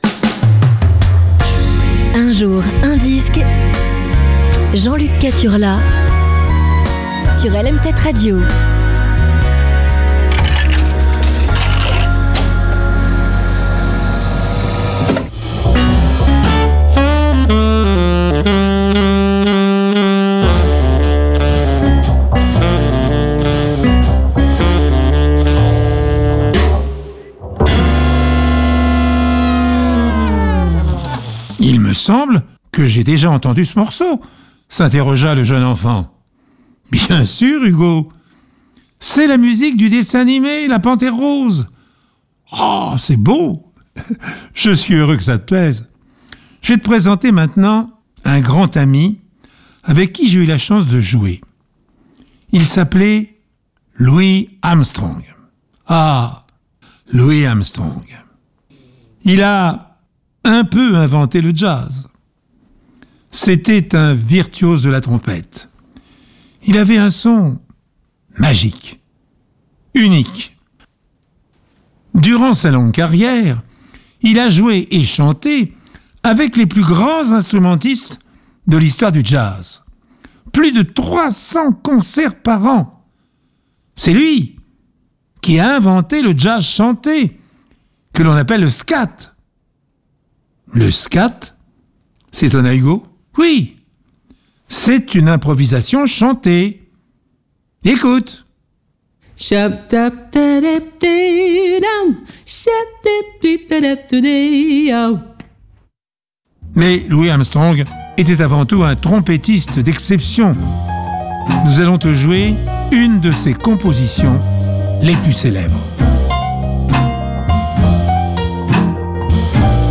racontée par Pierre Bellemare